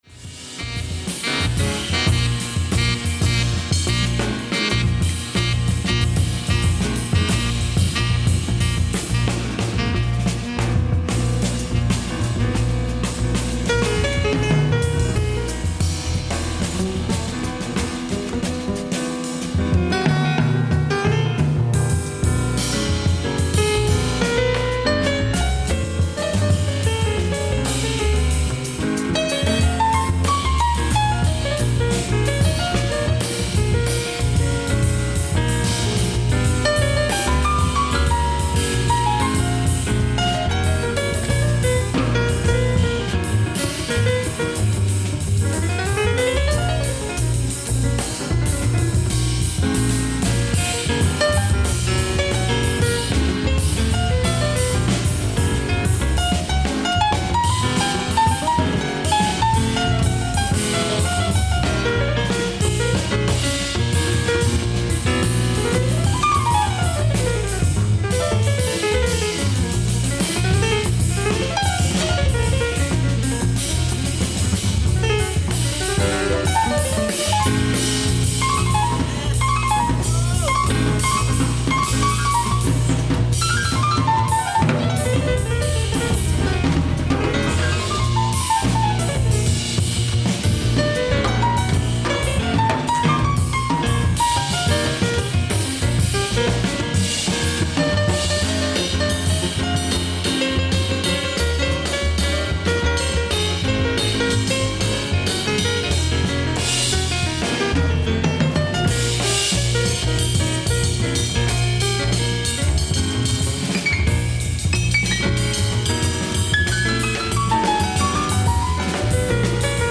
ライブ・アット・ＮＤＲ ジャズワークショップ、ハノーファー、ドイツ
※試聴用に実際より音質を落としています。